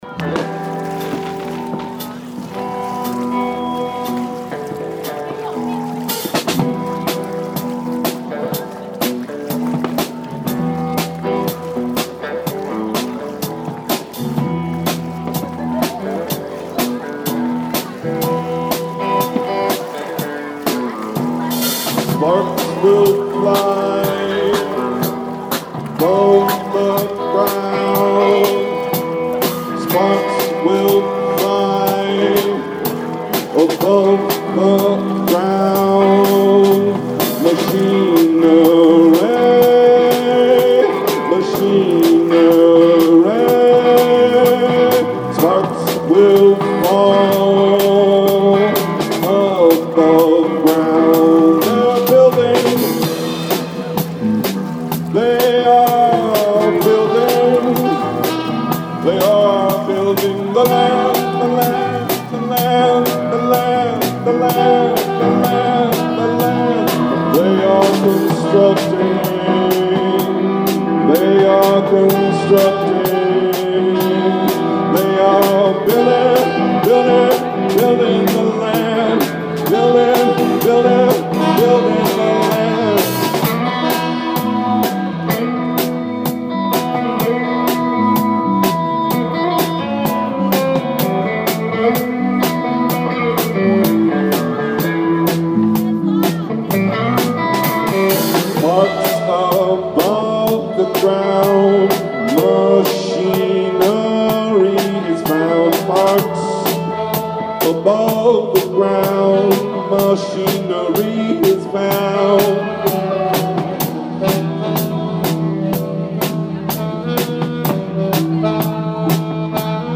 guitar
sax
drums
bass/voice
ALL MUSIC IS IMPROVISED ON SITE